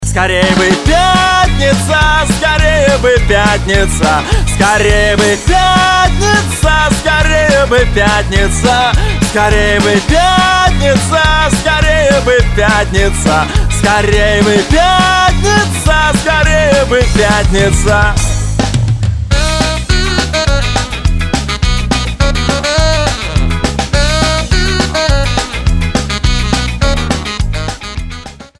• Качество: 192, Stereo
танцы